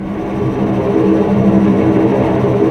Index of /90_sSampleCDs/Roland L-CD702/VOL-1/STR_Vcs Tremolo/STR_Vcs Trem p